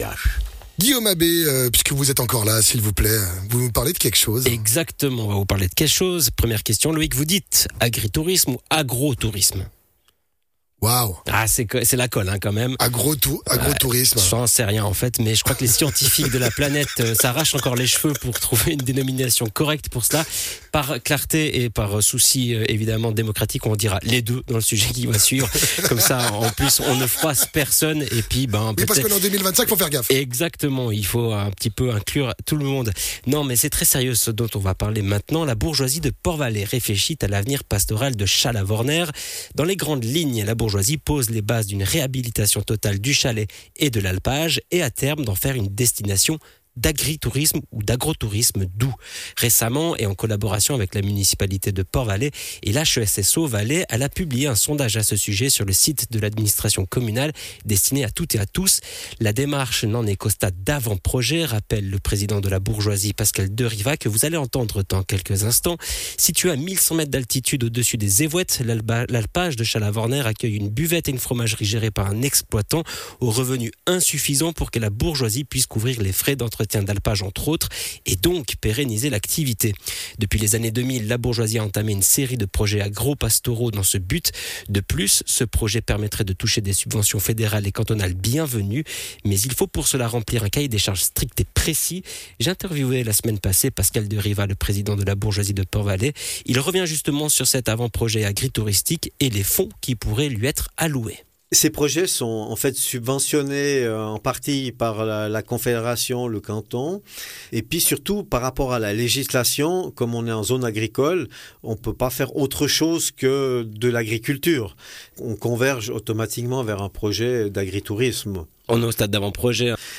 Intervenant(e) : Pascal Derivaz - Président de la Bourgeoisie de Port-Valais